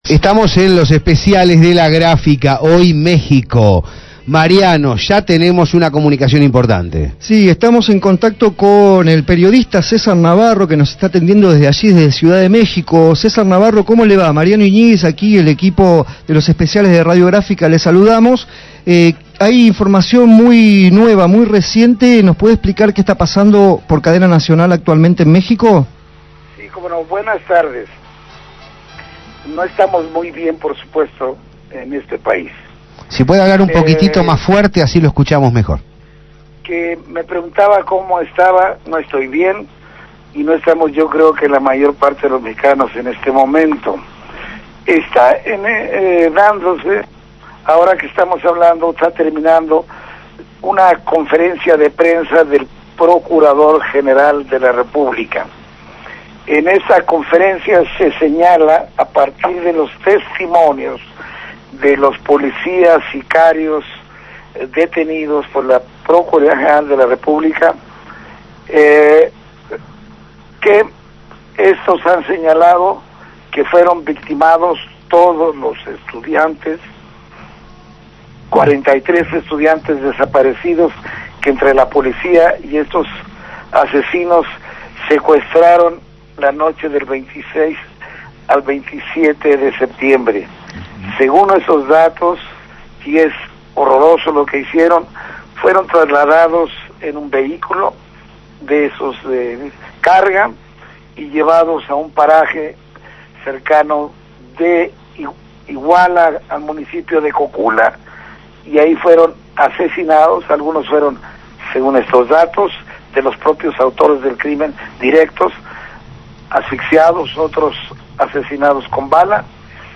En la tarde de hoy se llevó a cabo el Especial de Radio Gráfica sobre la actualidad mexicana. 43 estudiantes rurales continúan desaparecidos.